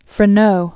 (frĭ-nō), Philip Morin 1752-1832.